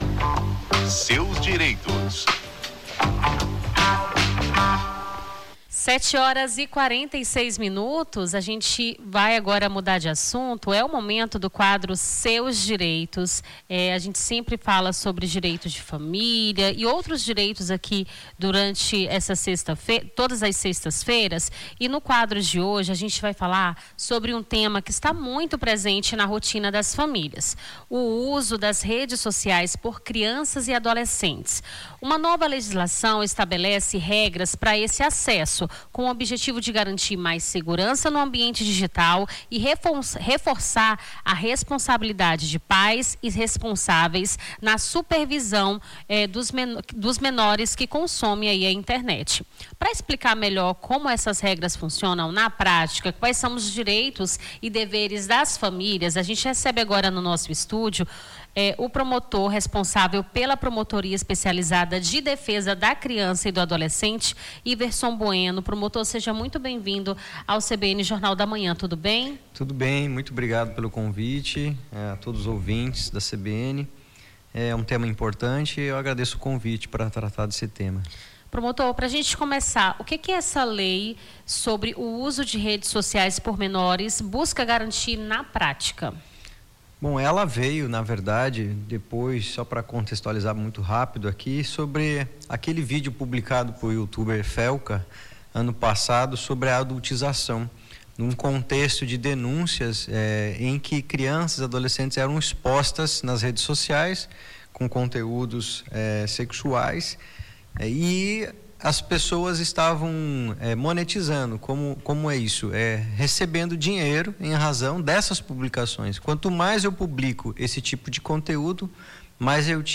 Na manhã desta sexta-feira, 06, conversamos com o promotor responsável pela Promotoria Especializada de Defesa da Criança e do Adolescente, Iverson Bueno, que falou sobre a lei Felca.